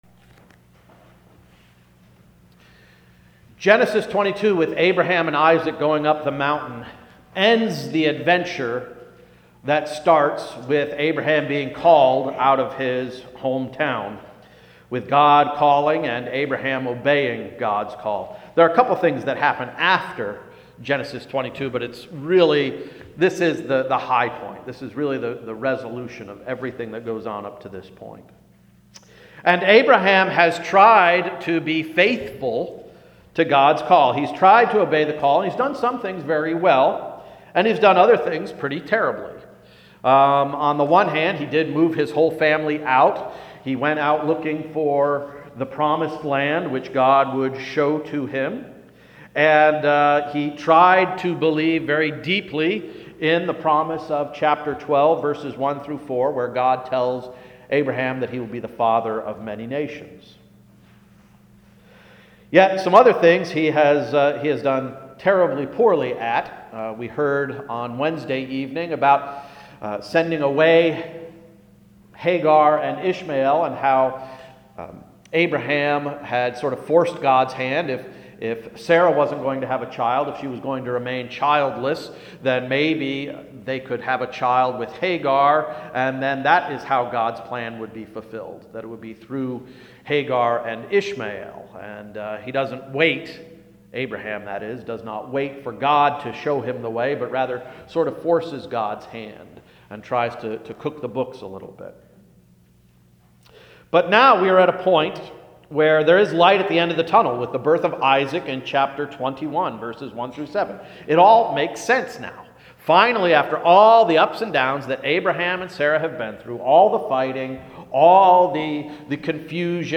February 14, 2016 Sermon– “Sense and Nonsense”